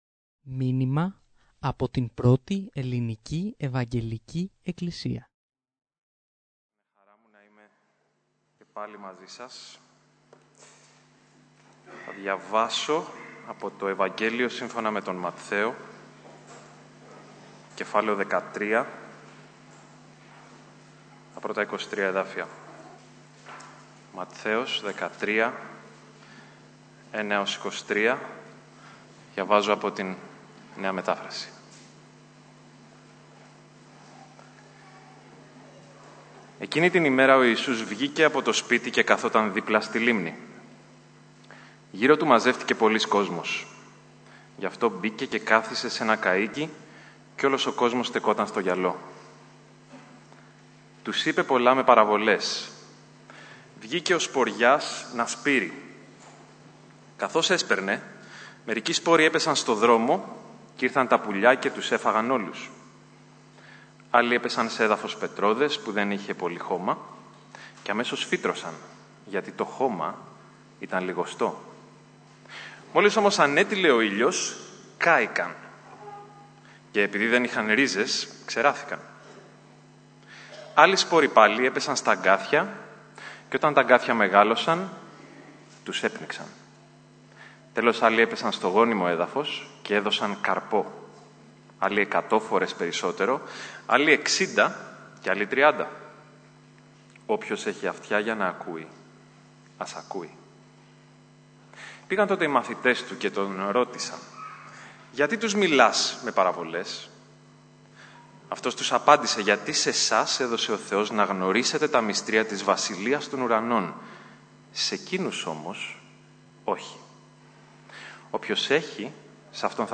Κυρήγματα | AEEE